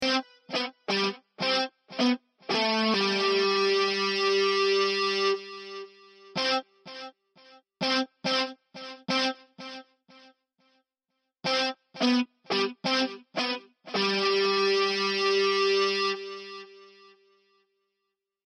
Luftgitarre